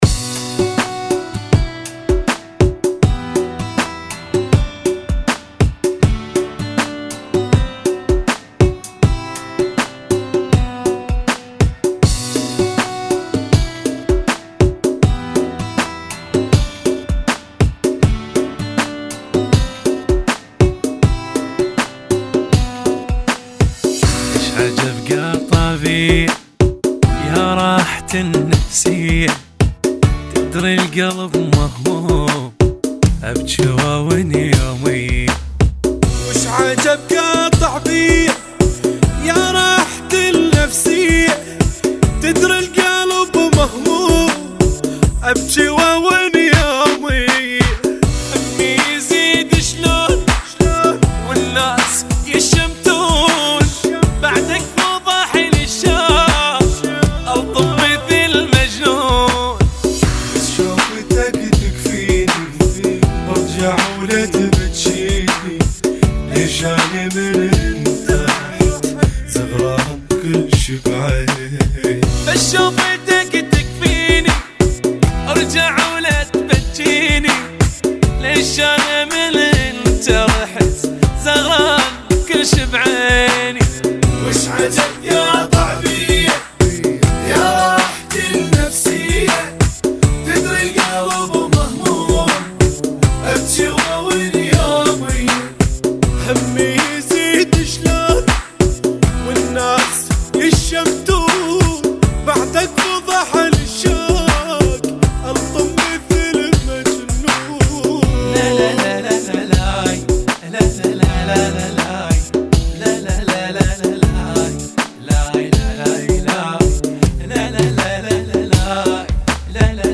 [ 80 Bpm ]